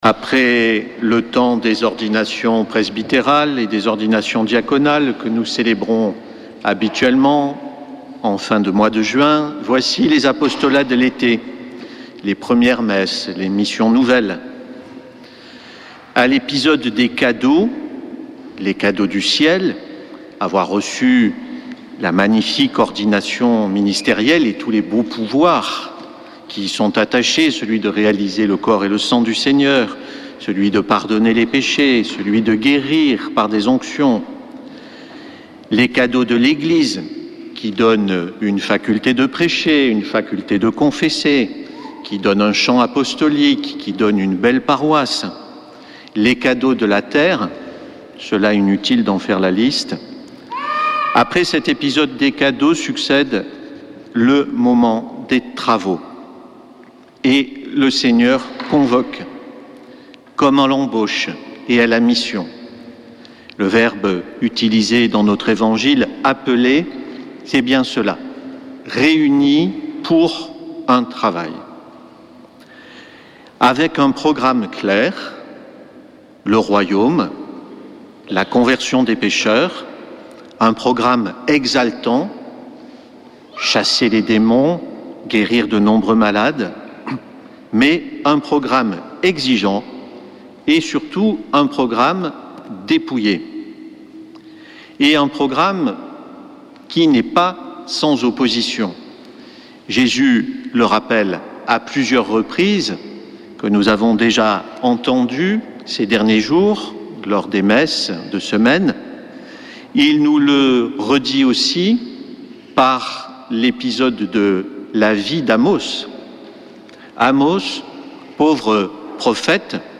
Homélie du 14 juillet - Radio Présence
dimanche 14 juillet 2024 Messe depuis le couvent des Dominicains de Toulouse Durée 01 h 30 min
Frères de la communauté